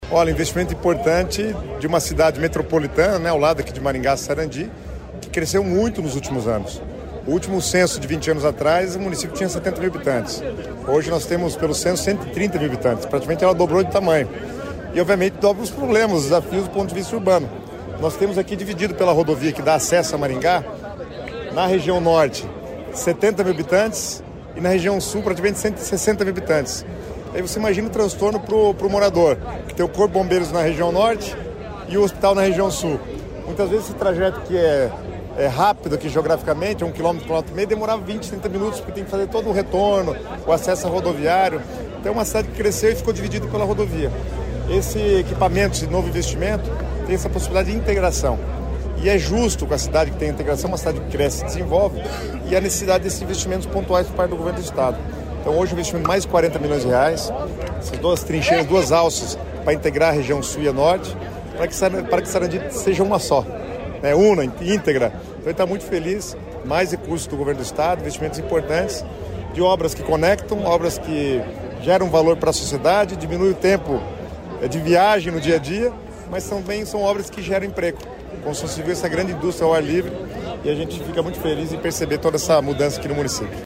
Sonora do secretário Estadual das Cidades, Guto Silva, sobre a inauguração dos viadutos em Sarandi sobre a BR-376